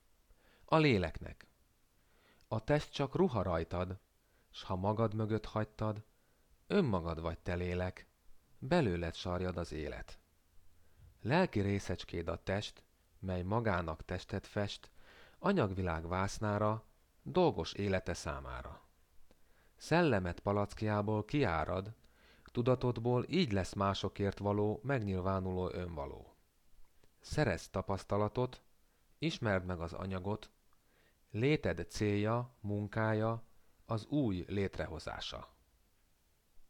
2009 májusában jutott rá időm, hogy valóra váltsam sok éve dédelgetett álmomat és gépre mondjam a kedvenc verseimet.